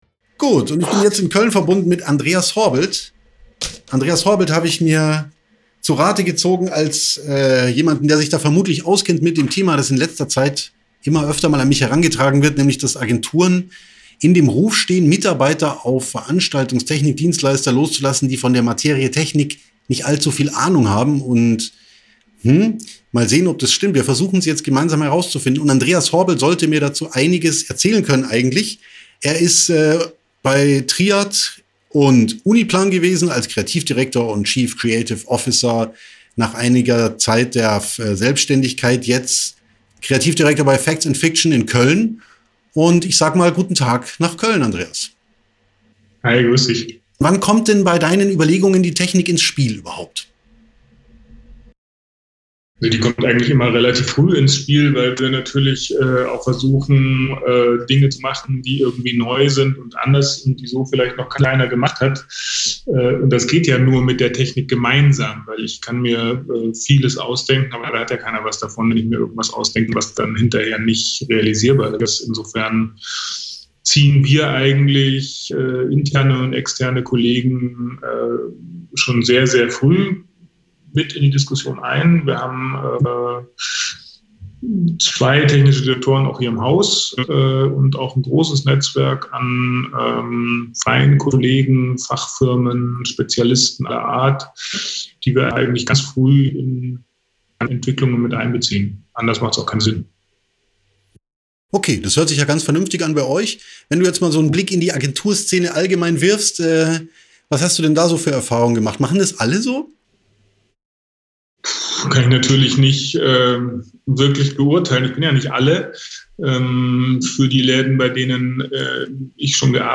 Eine Stimme aus der Agenturwelt: